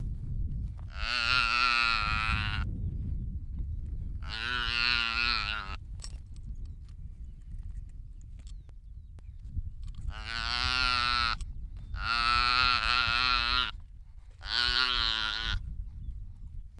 вариант 3 с маленькой сайгой